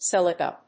/ˈsɛlɪkʌ(米国英語), ˈselɪkʌ(英国英語)/